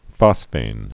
(fŏsfān)